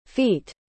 Pronúncia de feet
feet.mp3